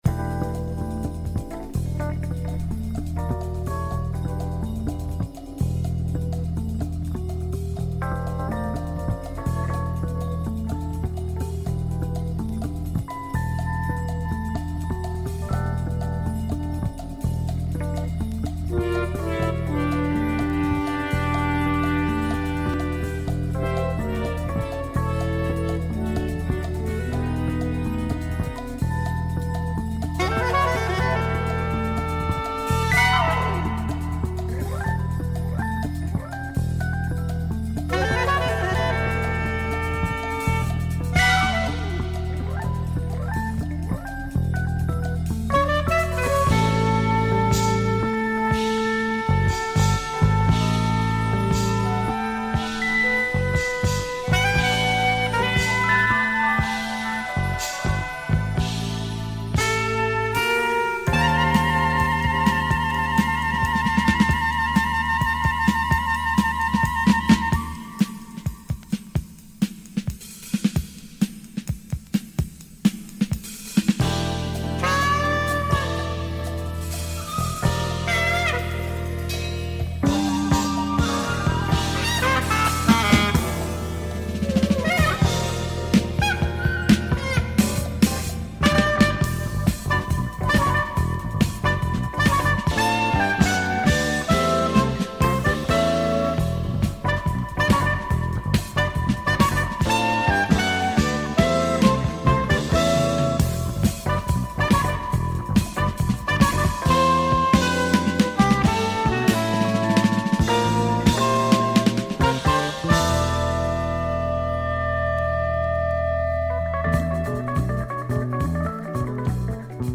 Killer jazz groove from Catalunya !